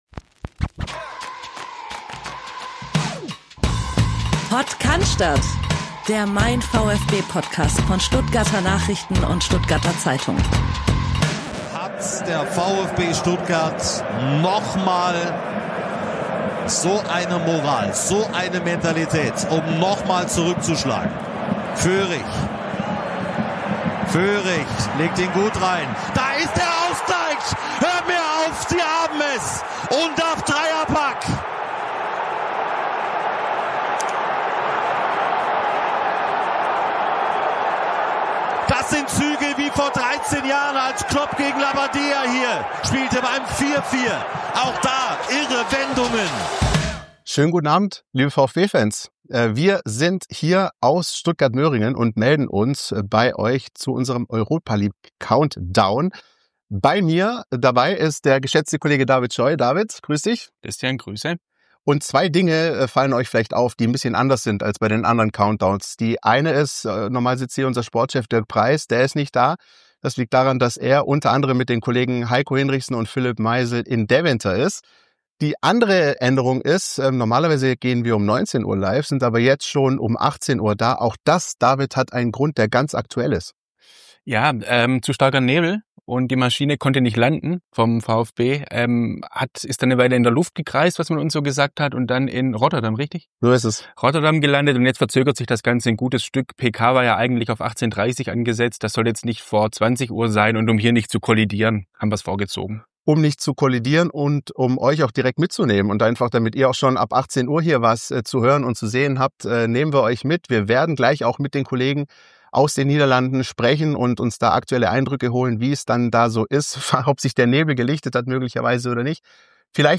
Es handelt sich um ein Audio-Re-Live des YouTube-Streams von MeinVfB.